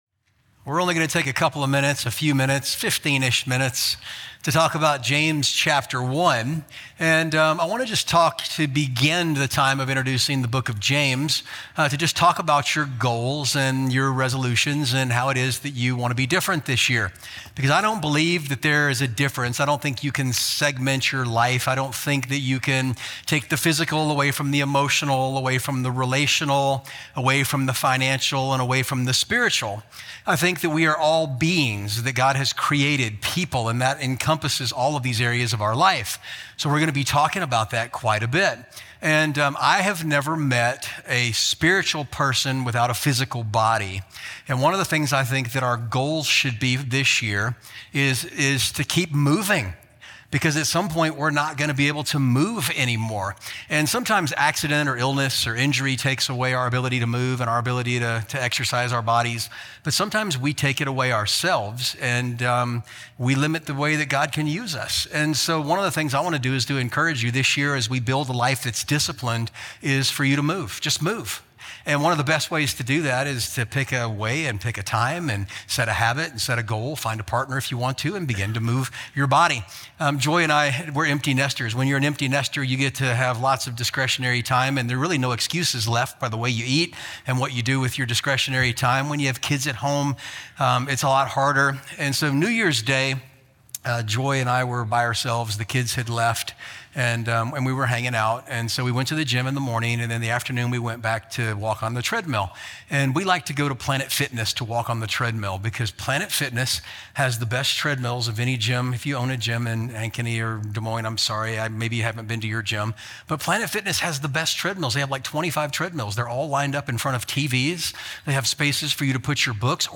Capitol City Church Podcast (Sermon Audio) (Sermon Audio)